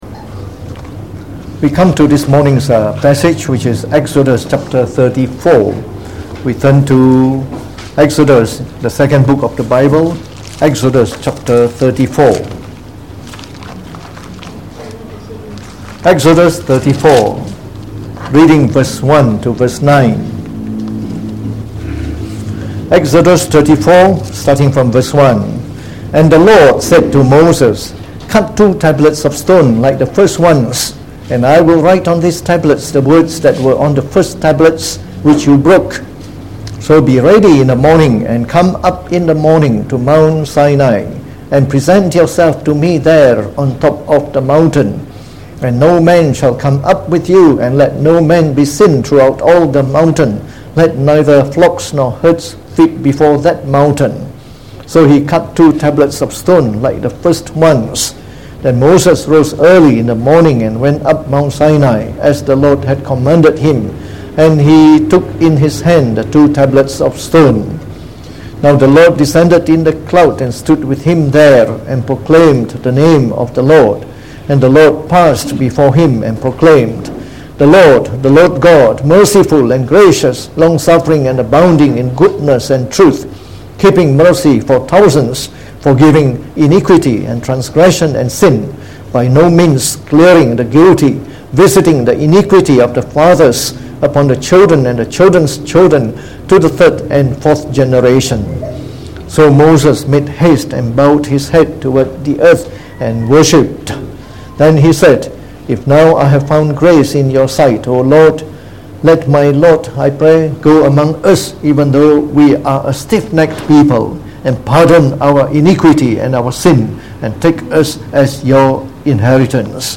Sermon
delivered in the Morning Service